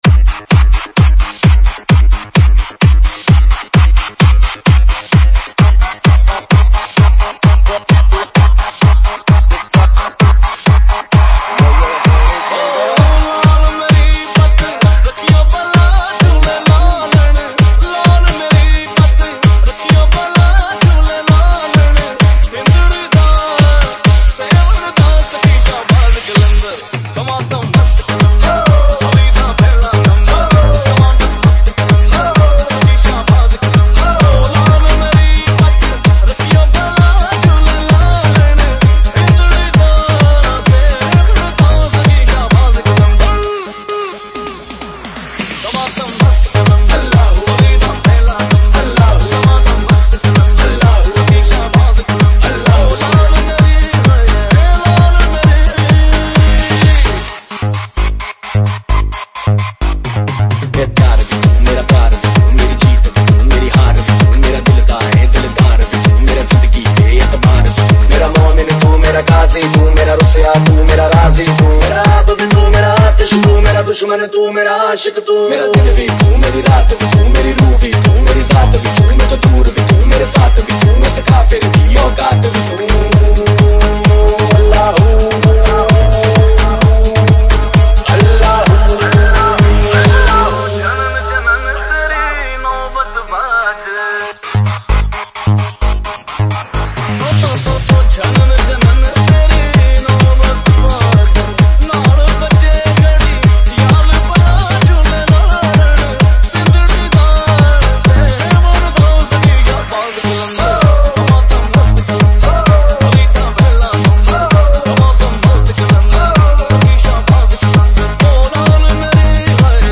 Dj Mix Hindi Song [1000 ]